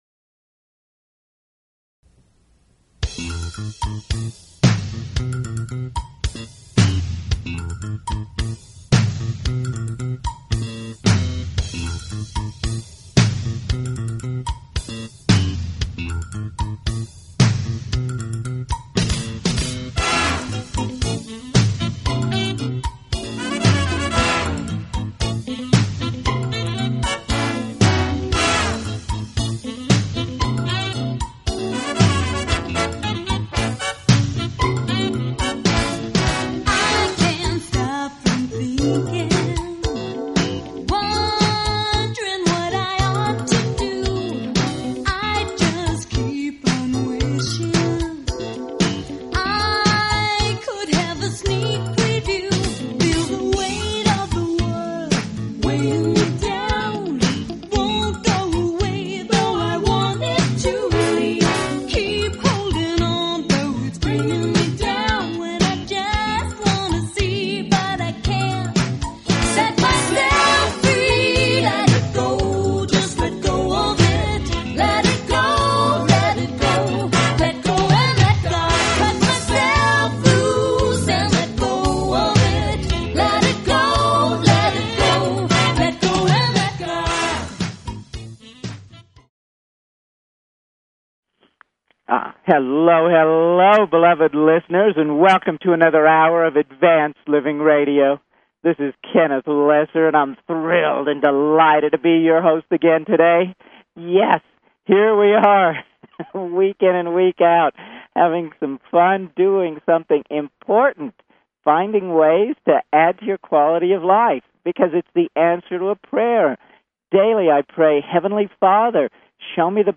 Talk Show Advanced Living